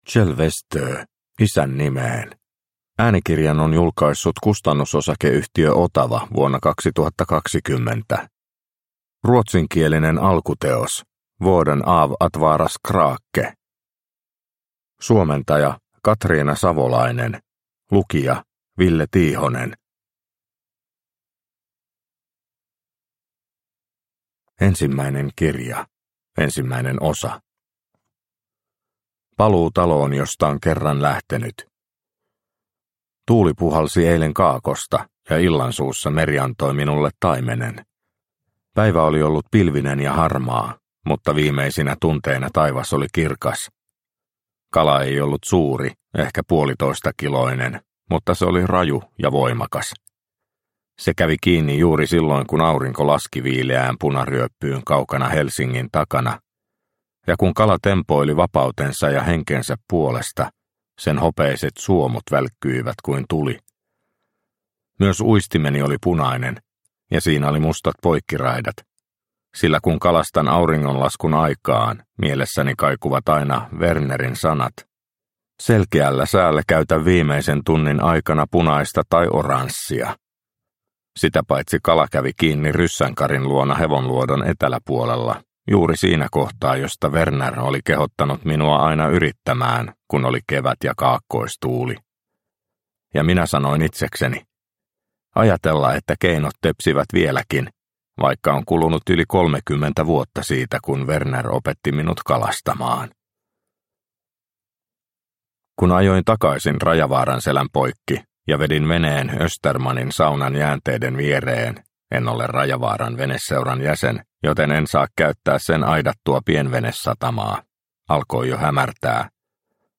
Isän nimeen – Ljudbok – Laddas ner